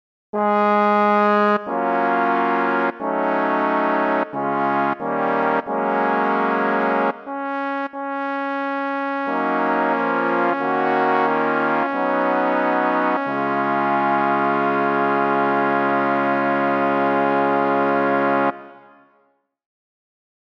Key written in: D♭ Major
How many parts: 4
Type: Barbershop
All Parts mix: